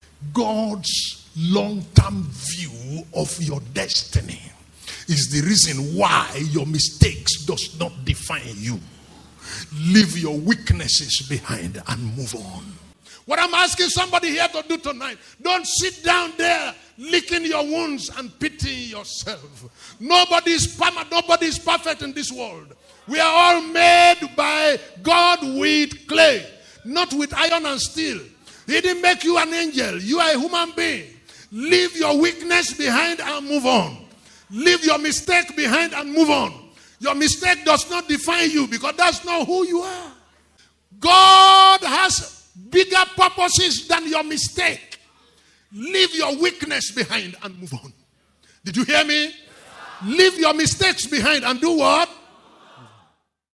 This Sermon tells us to leave our weakness and mistakes.